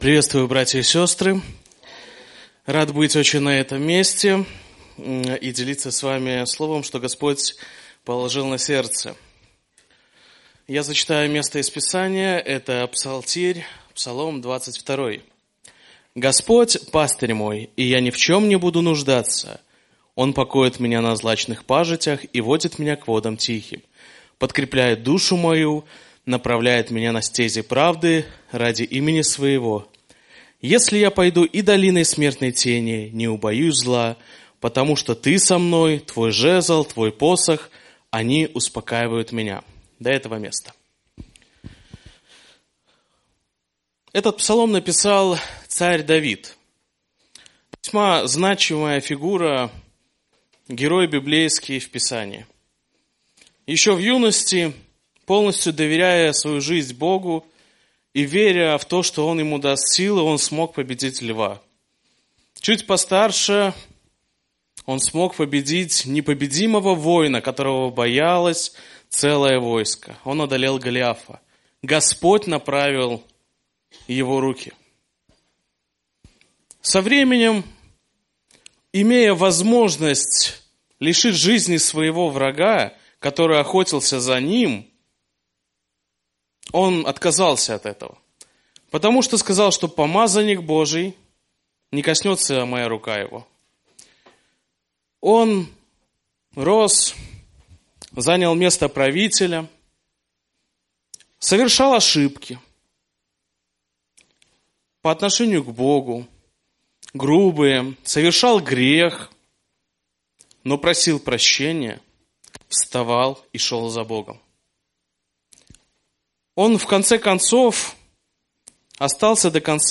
Набажэнства (ЧЦ) - 28 лістапада 2019
Пропаведзь